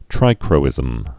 (trīkrō-ĭzəm)